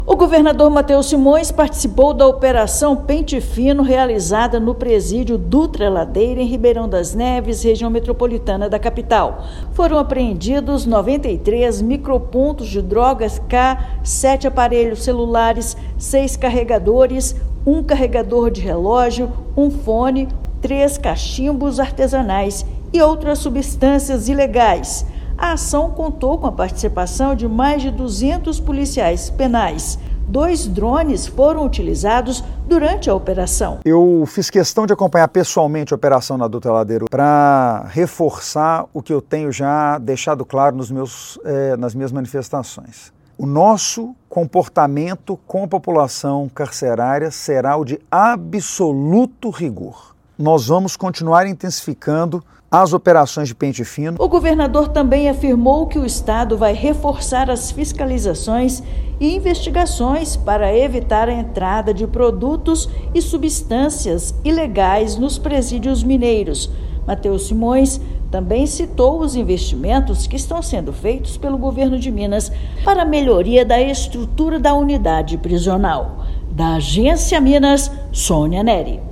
Governador acompanhou a ação, que resultou na apreensão de drogas, telefones celulares e outros materiais. Ouça matéria de rádio.